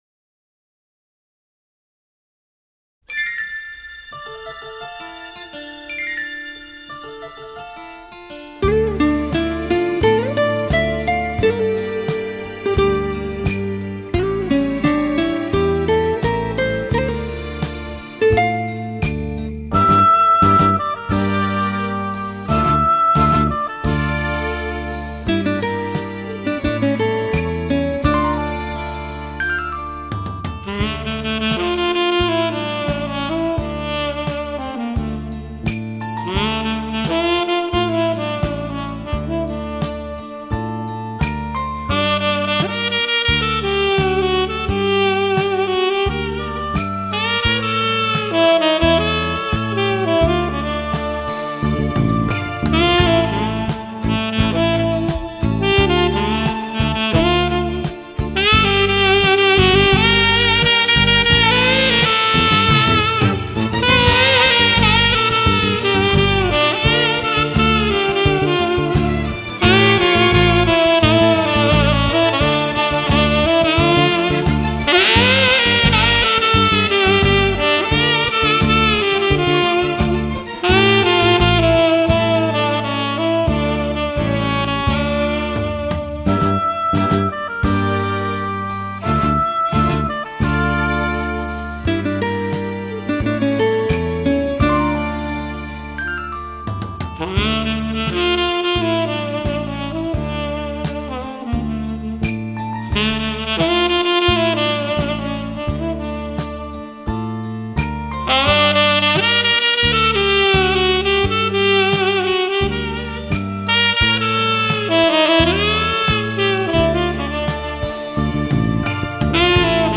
на саксофоне